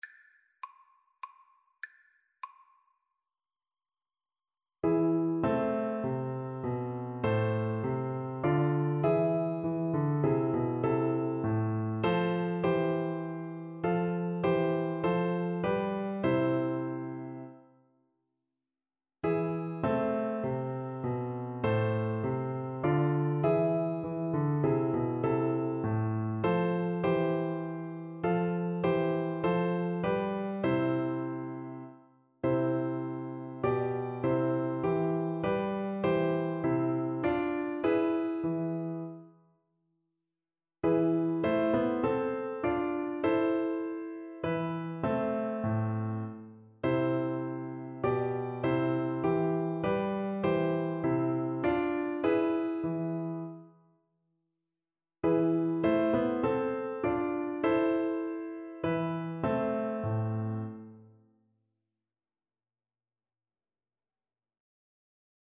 Moderato
Classical (View more Classical Flute Music)